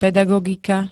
Zvukové nahrávky niektorých slov
mtrs-pedagogika.spx